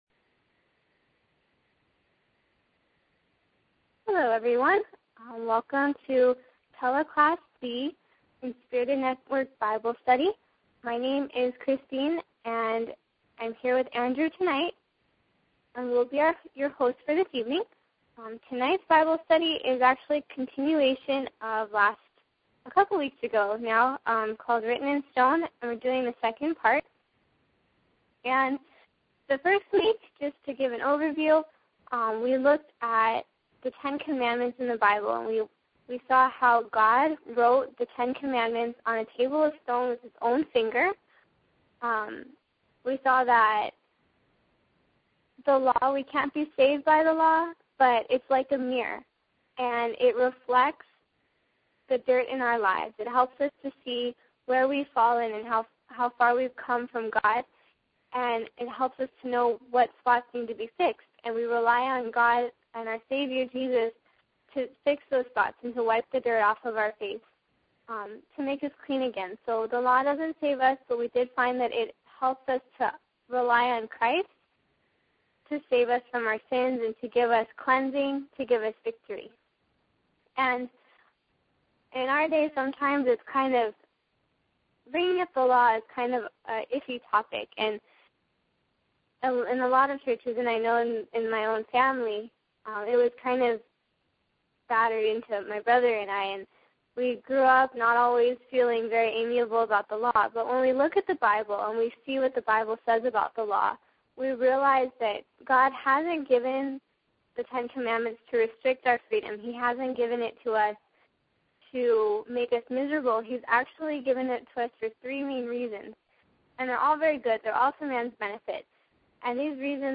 Sermon touches Bible prophecy: the sealing and the 7 last plagues.